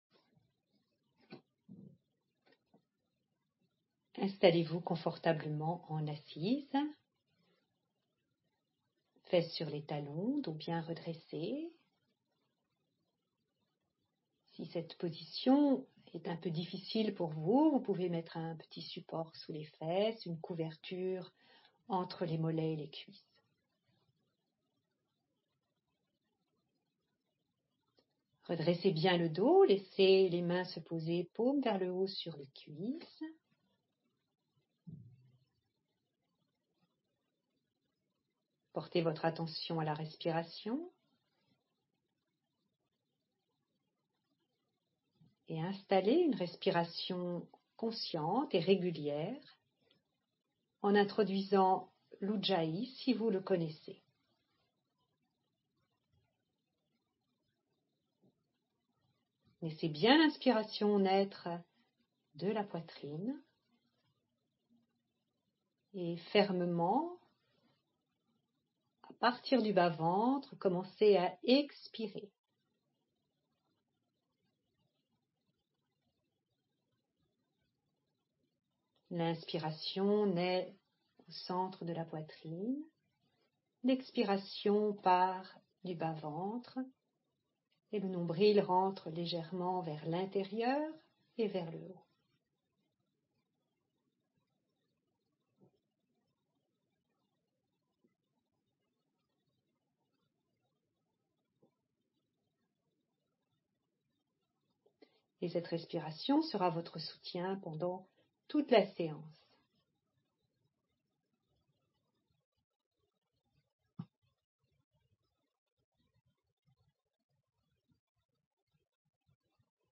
Pour écouter la séance guidée